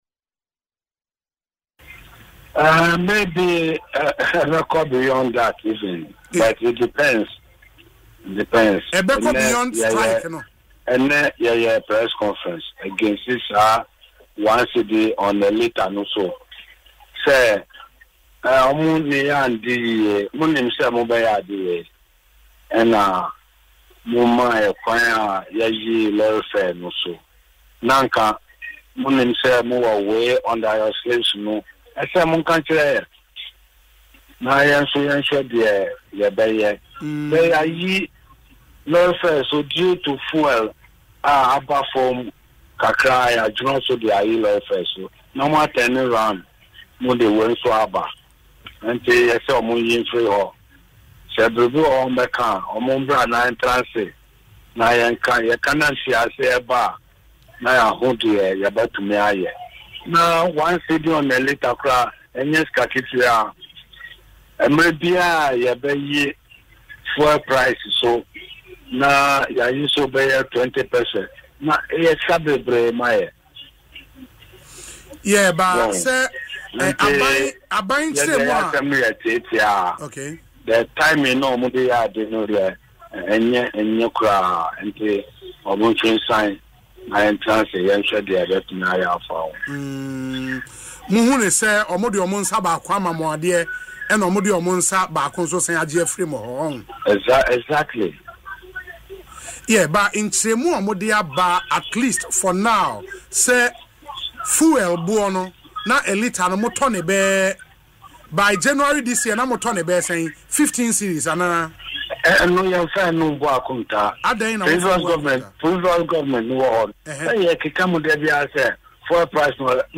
In an interview on Adom FM’s Dwaso Nsem